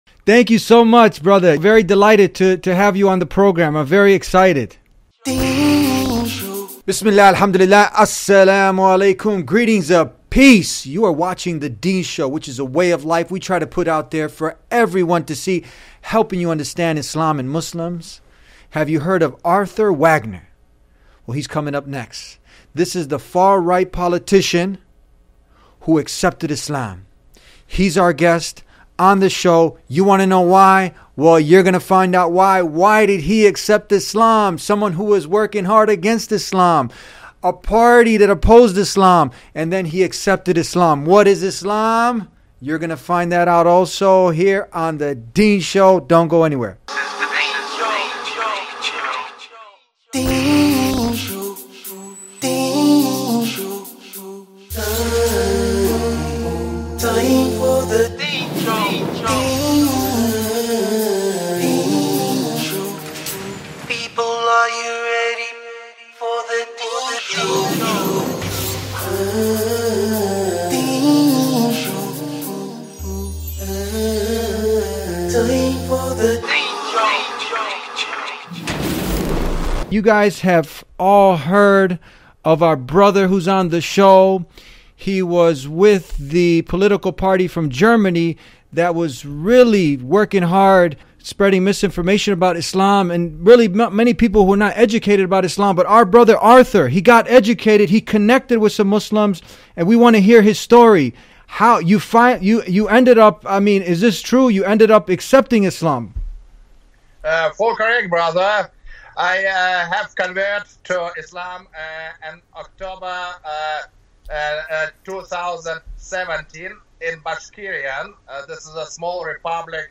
EXCLUSIVE INTERVIEW WITH FAR-RIGHT WING Politician from GERMANY who accepted ISLAM Arthur Wagner